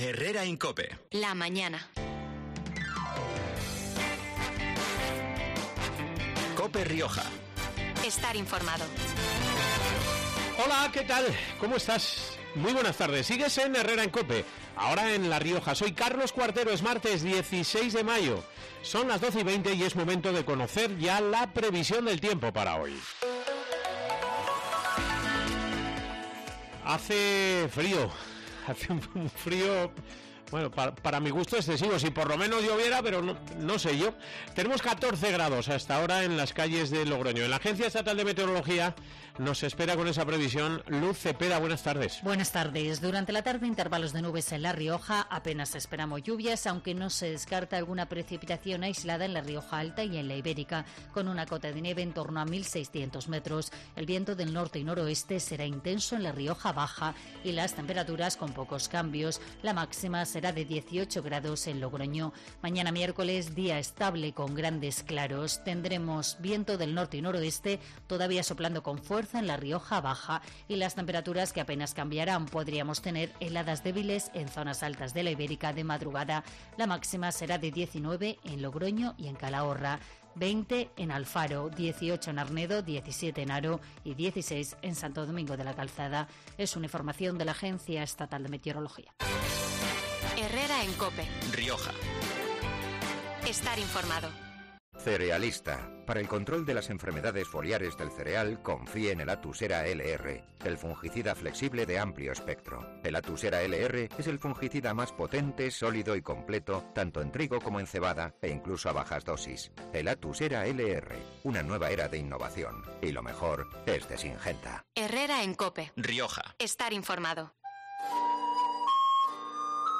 Nuestro primer invitado del 16 de mayo en COPE Rioja es uno de los escritores de novela histórica más leídos de nuestro país, gracias no sólo a su habilidad para trenzar historias que seducen al lector desde sus primeras páginas, sino también, y sobre todo, al profundo conocimiento que atesora de un período de nuestra historia tan apasionante como quizá todavía desconocido para el gran público: la Edad Media.